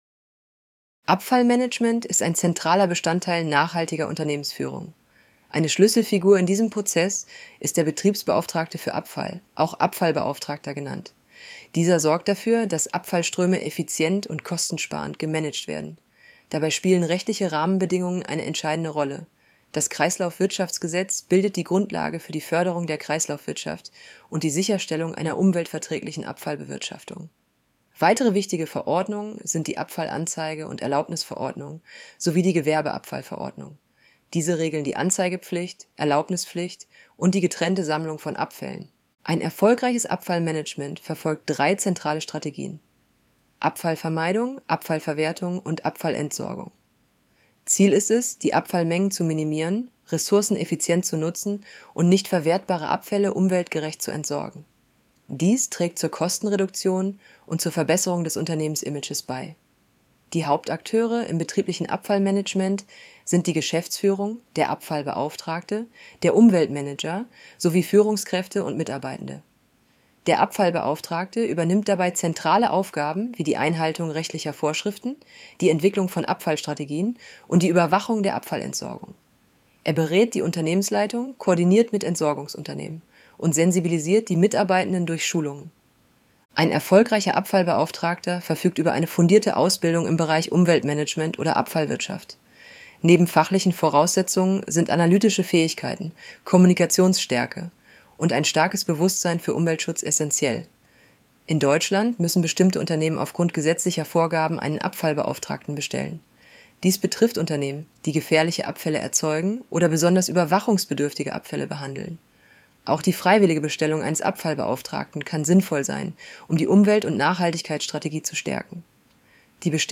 Hinweis: Diese Vorlesefunktion verwendet eine synthetisch erzeugte Stimme aus einem KI-System.
Die Stimme ist keine Aufnahme einer realen Person.